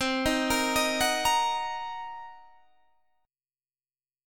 Cm7b5 Chord
Listen to Cm7b5 strummed